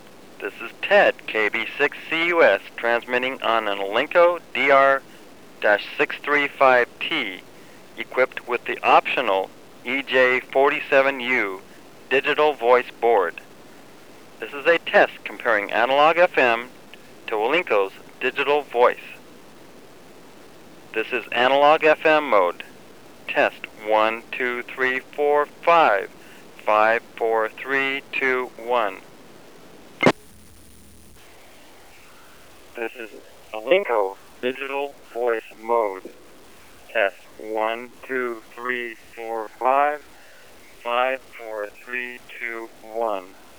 (DR-635T) ANALOG FM vs DIGITAL VOICE
alinco-dv-1.wav